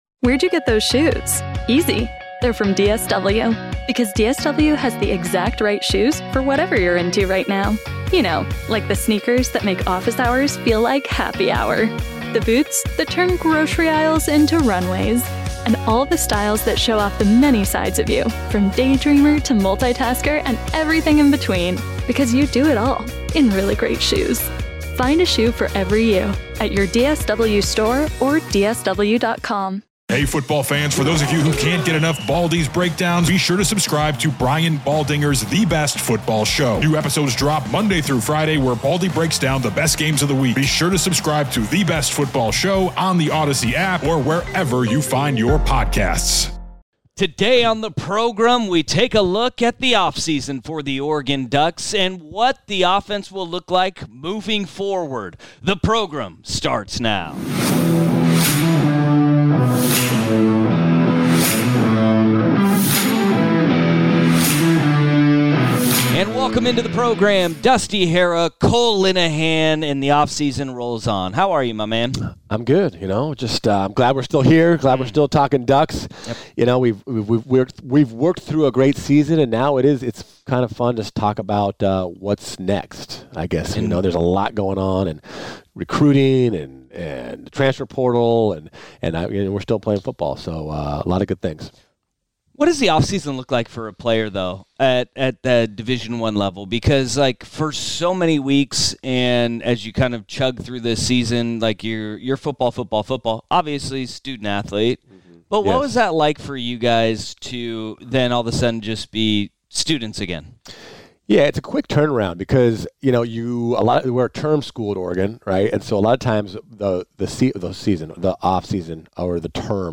Portland's iconic sports talk show.